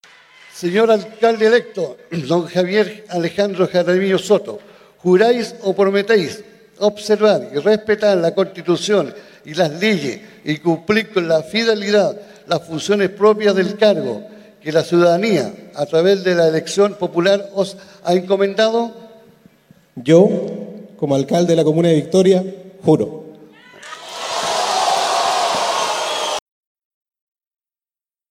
Hasta el gimnasio del Liceo B-10 de la comuna de Victoria llegó el alcalde reelecto de esta comuna, Javier Jaramillo Soto, quien hizo uso de un permiso de dos horas para poder asistir a esta sesión especial del Concejo y prestar juramento en un recinto repleto de adherentes.
De todas formas, el jefe comunal prestó juramento y agradeció el apoyo que le brindaron los vecinos que llegaron portando carteles para manifestarle su apoyo.
cu-juramento-jaramillo.mp3